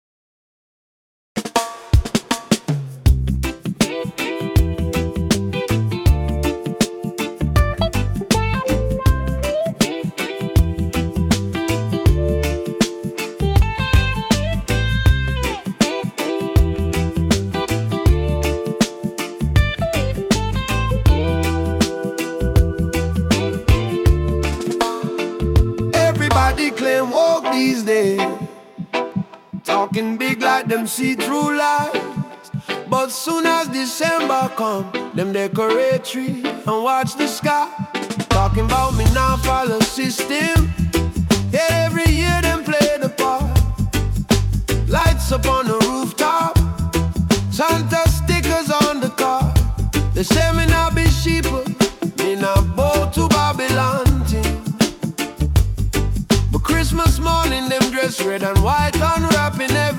🎧 Genre: Reggae